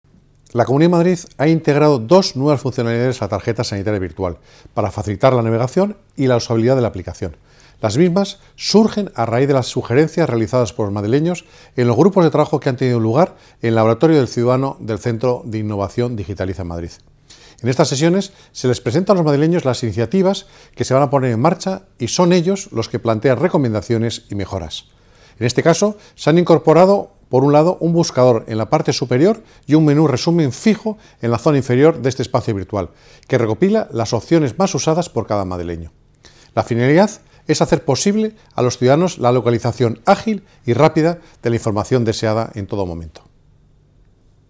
Declaraciones del Consejero en la página web de la Comunidad de Madrid con la nota de prensa]